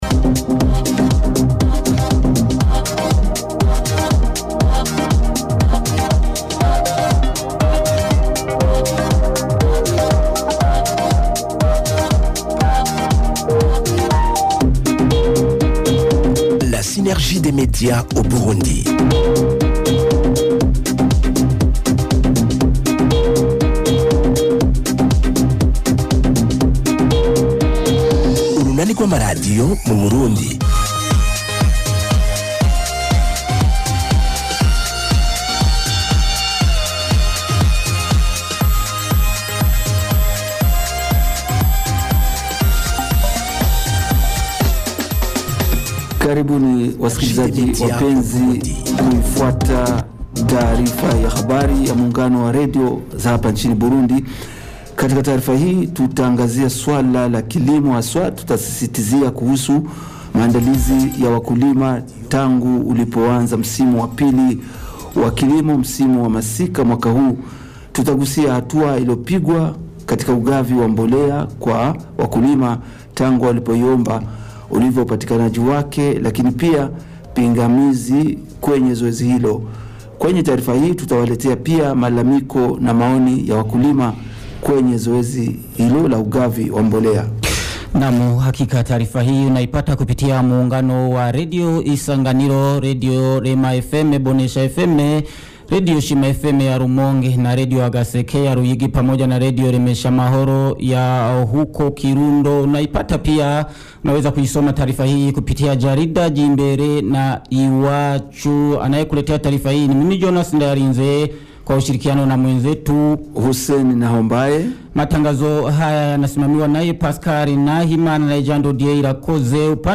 Taarifa ya habari ya muungano wa redio ya tarehe 26 Februari 2026